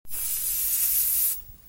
Звуки аэрозоля
Звук шипения спрея